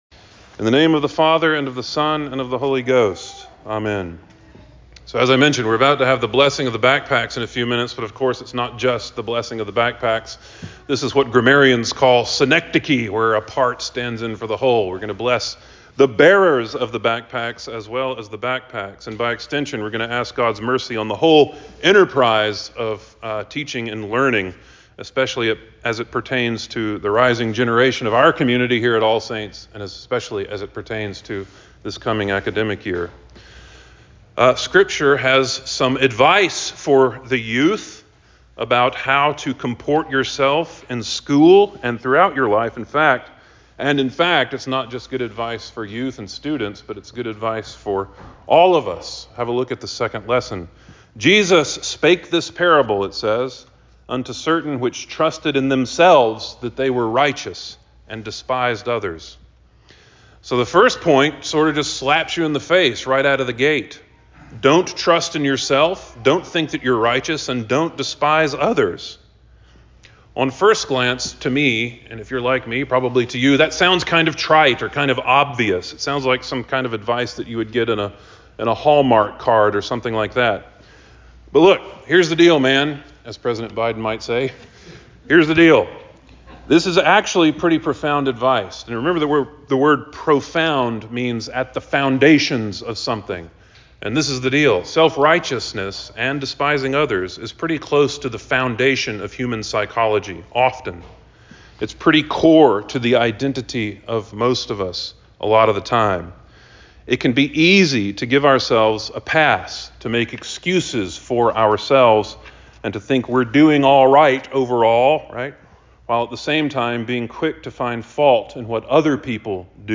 Sermon for the Eleventh Sunday After Trinity 08.11.24